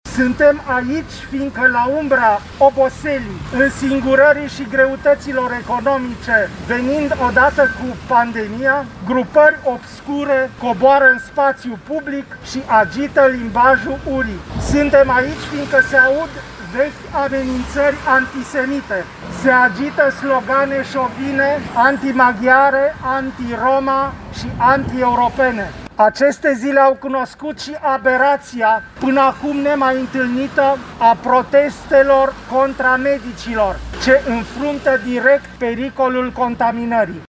Zeci de timișoreni, scriitori, medici, profesori reprezentanți ai societății civile s-au strâns în această după-amiază în fața Primăriei Timișoara, în semn de protest față de manifestările care au avut loc în fața casei primarului, la finalul lunii martie, în care s-au strigat lozinci xenofobe.